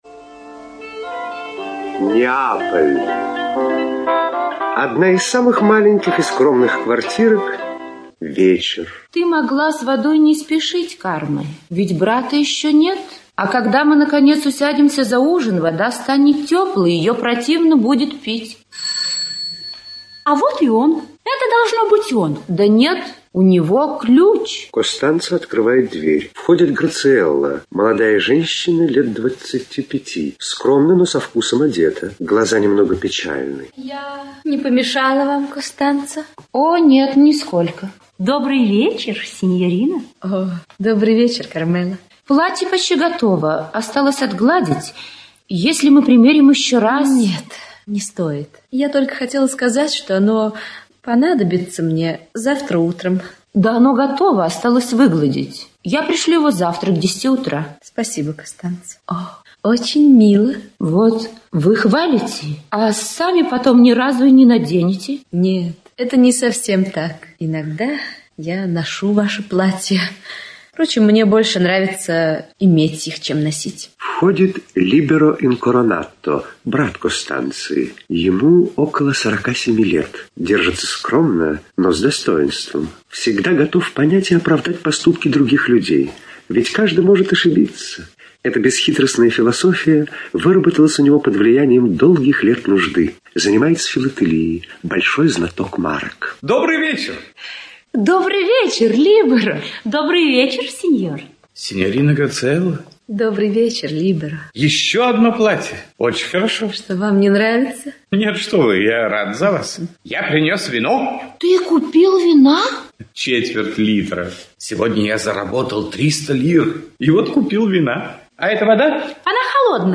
Аудиокнига Ложь на длинных ногах (спектакль) | Библиотека аудиокниг
Aудиокнига Ложь на длинных ногах (спектакль) Автор Эдуардо де Филиппо Читает аудиокнигу Актерский коллектив.